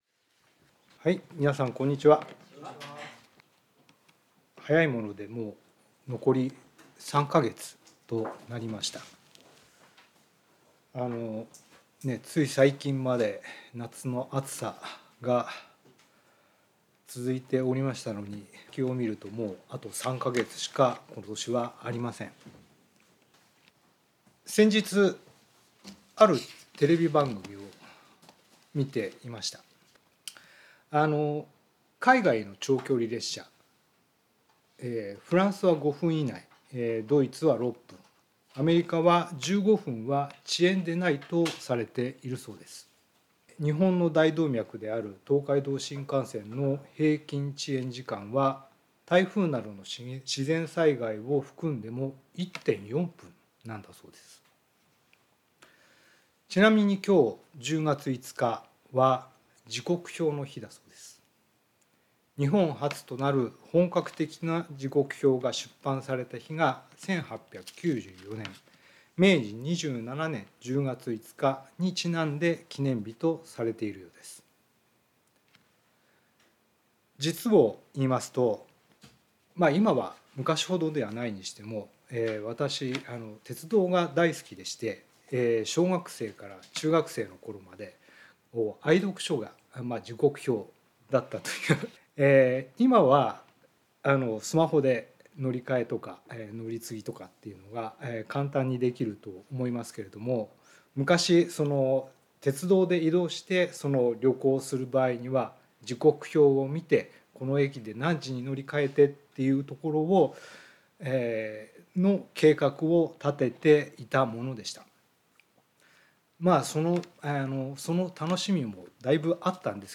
聖書メッセージ No.288